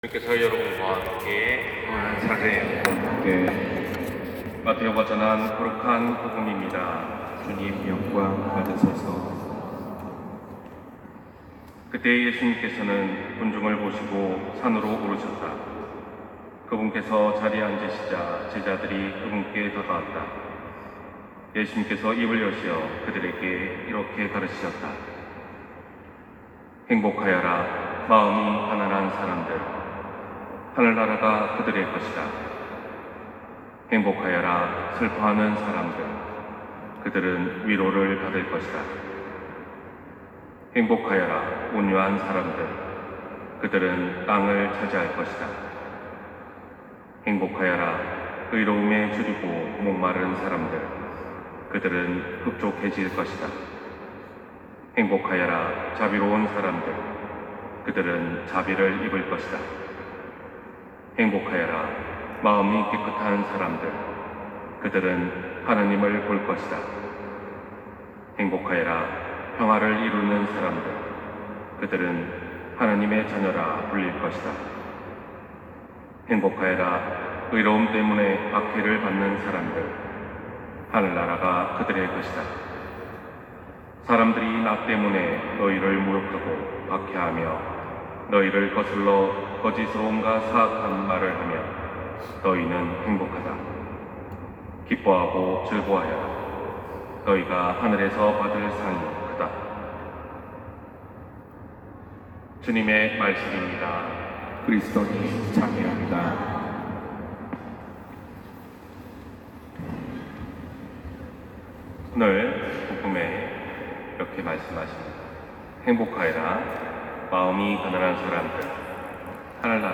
260131 신부님강론말씀